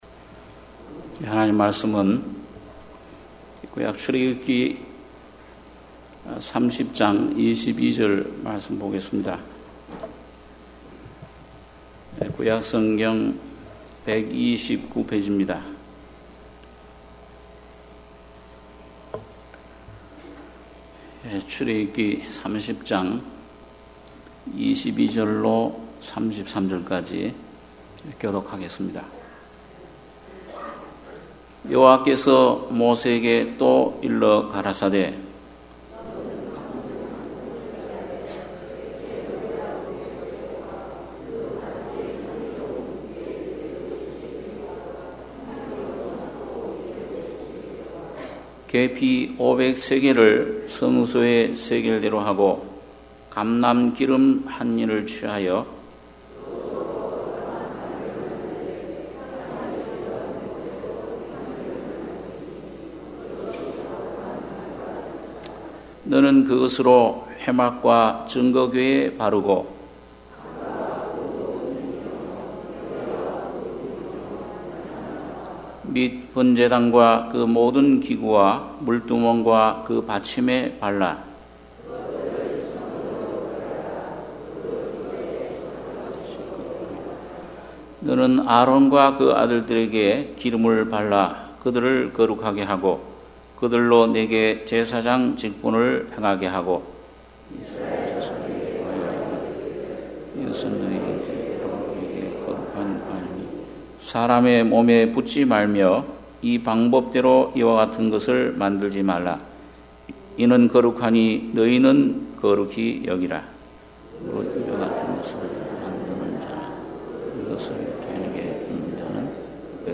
주일예배 - 창세기 49장 1-10절(주일오후)